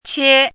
chinese-voice - 汉字语音库
qie1.mp3